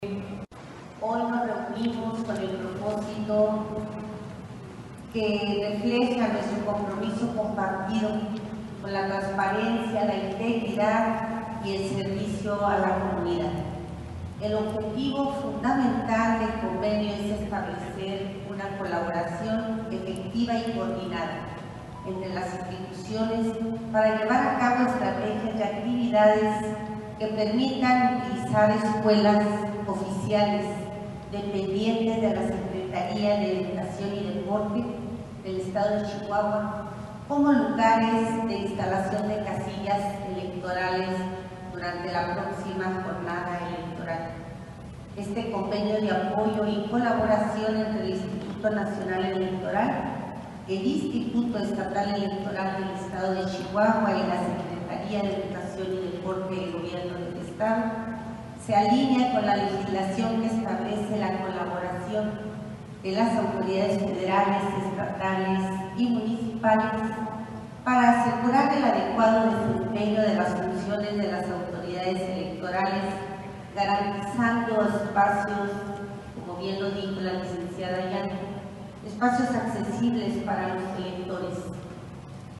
AUDIO: SANDRA GUTÍERREZ FIERRO, TITULAR DE LA SECRETARÍA DE EDUCACIÓN Y DEPORTE (SEyD)